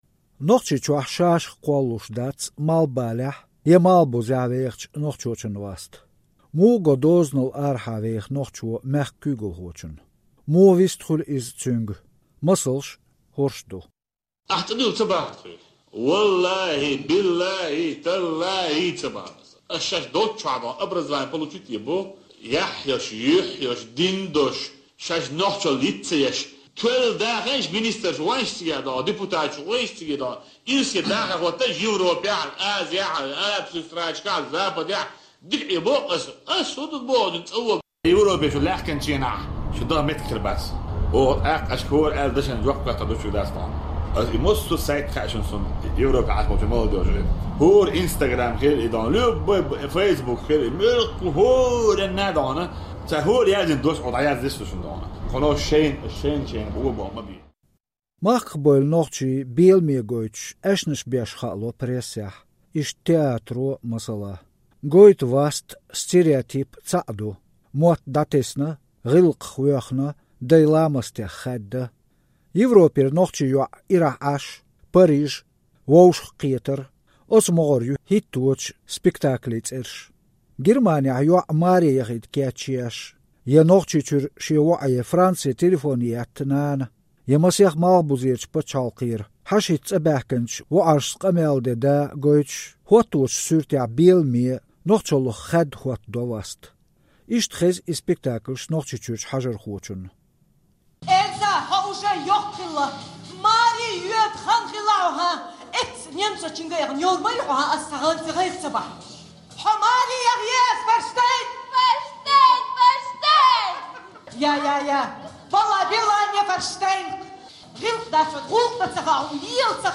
Театрера аьзнаш: «Эльза хьан маре йоьду хан хилла, эцца немцочуьнга а яхана йовр ма ю хьо!